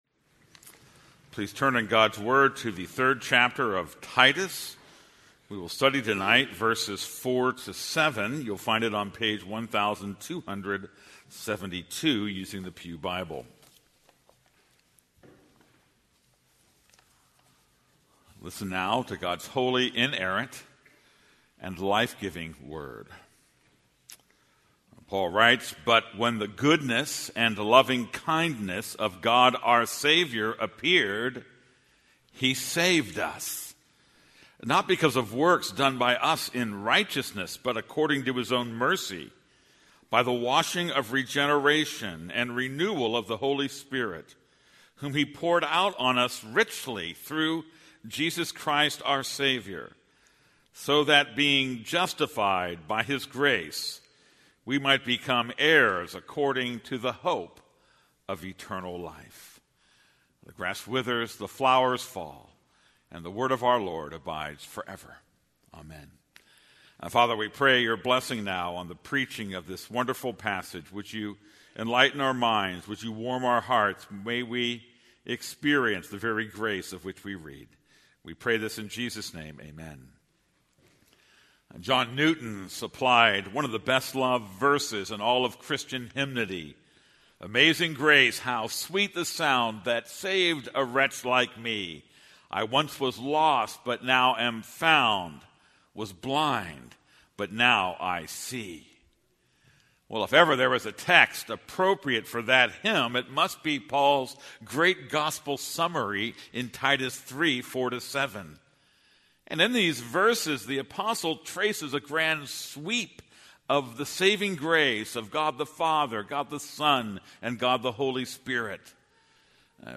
This is a sermon on Titus 3:4-7.